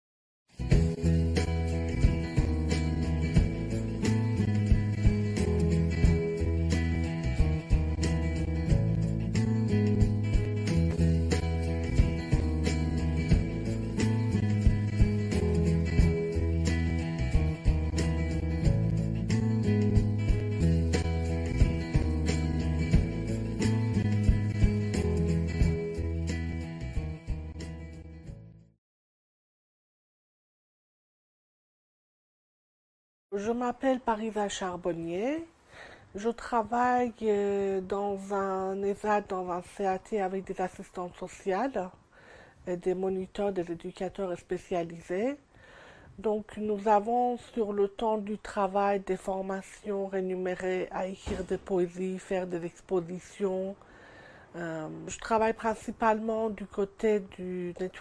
Replay 12/08 - Edilivre Interview